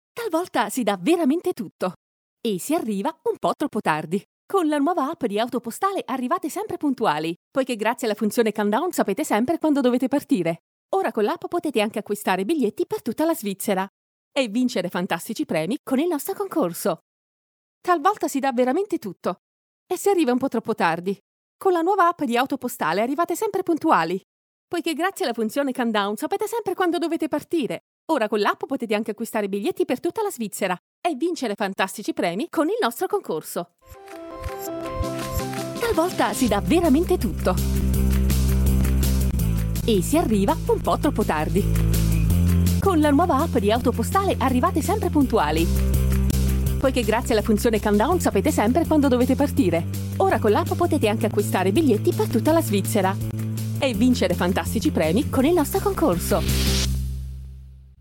Italian Voice Over
Kein Dialekt
Sprechprobe: Werbung (Muttersprache):